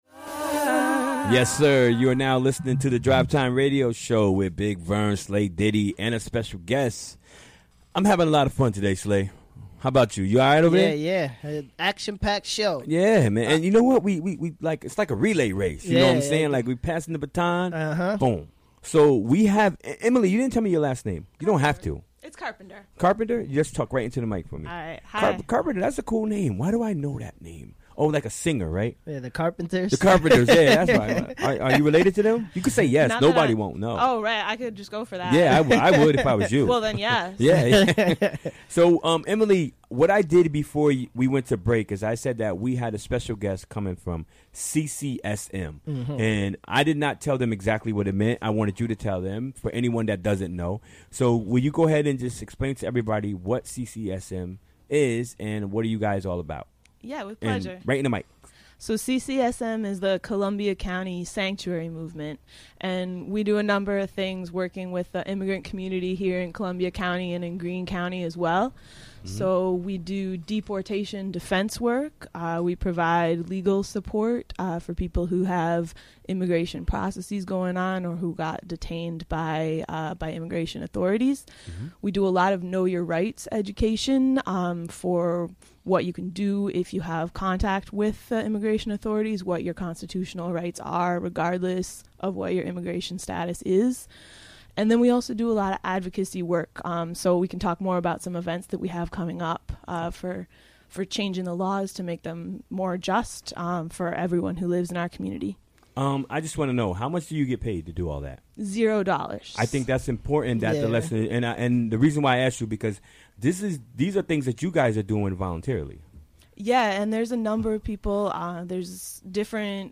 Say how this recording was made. Recorded during the WGXC Afternoon Show Wednesday, December 6, 2017.